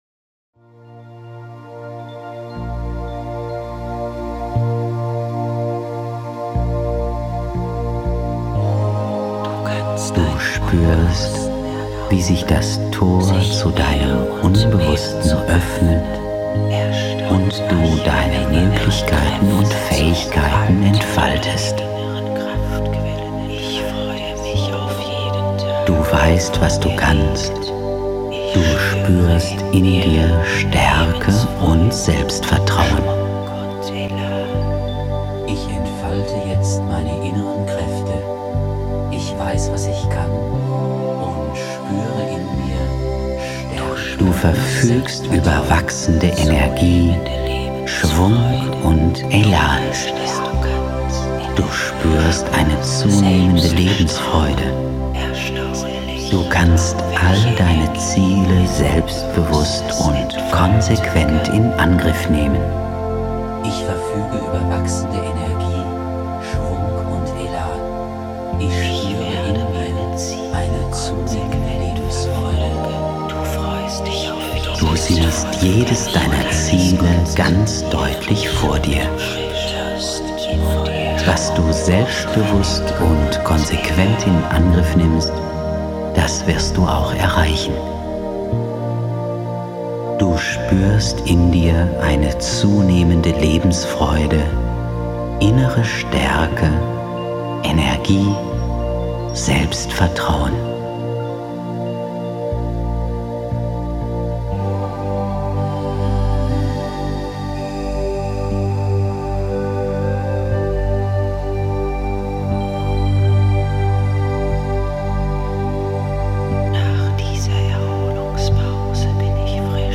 Tiefensuggestion
Setzen Sie den Kopfhörer auf, lehnen Sie sich bequem zurück − und schon nach wenigen Minuten werden Sie von der angenehmen Wirkung sanfter Worte und wohlklingender Musik überrascht.